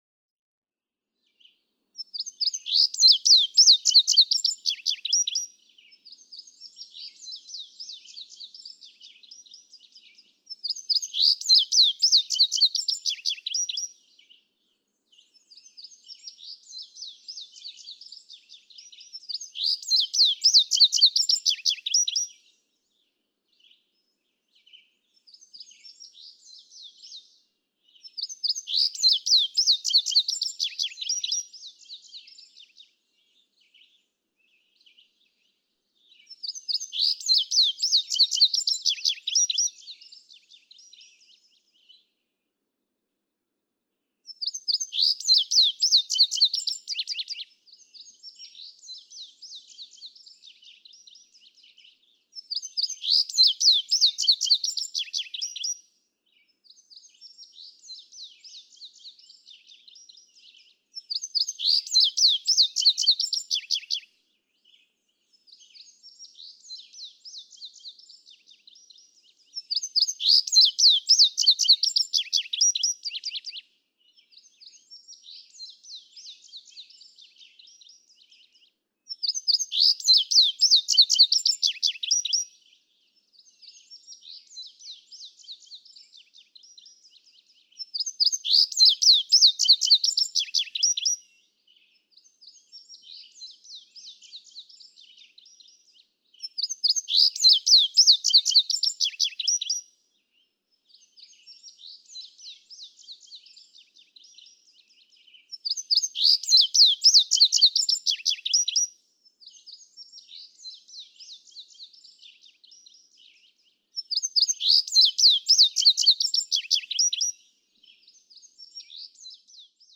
Tye River Gap, Blue Ridge Parkway, Virginia.
♫205, ♫206—longer recordings from those two neighbors
205_Indigo_Bunting.mp3